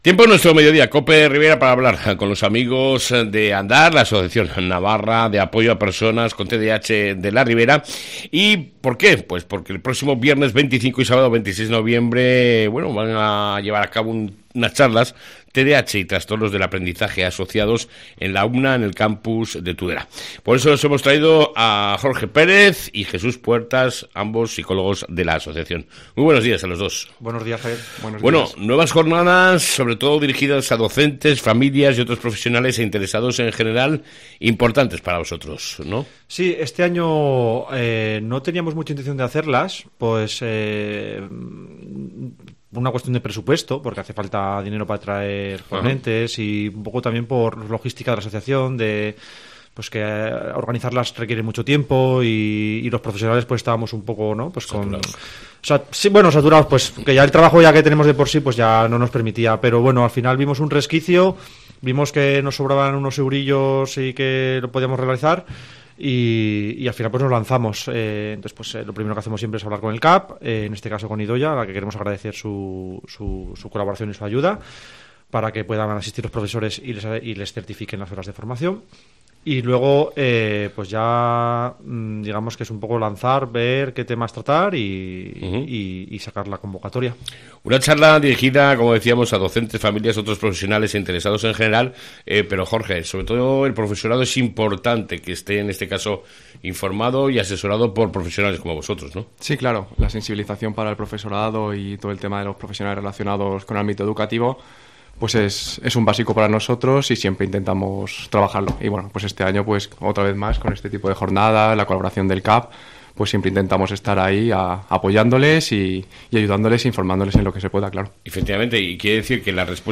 ENTREVISTA CON LA ASOCIACIÓN ANDAR